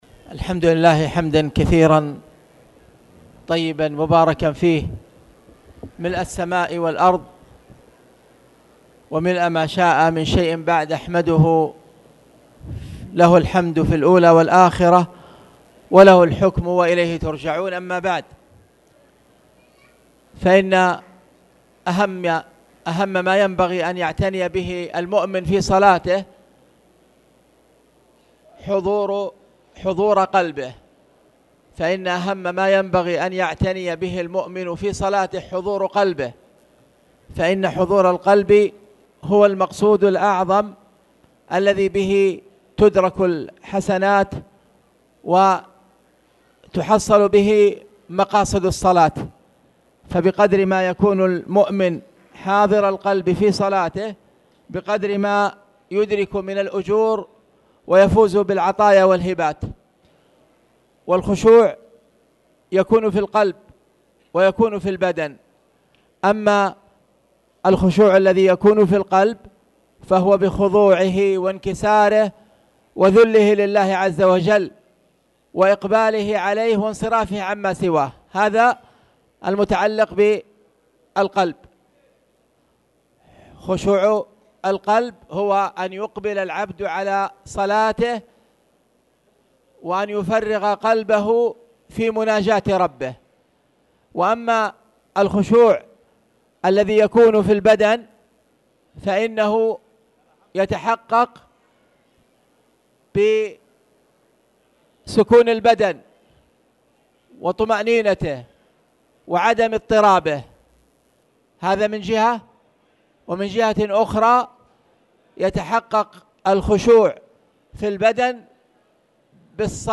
تاريخ النشر ٤ رجب ١٤٣٨ هـ المكان: المسجد الحرام الشيخ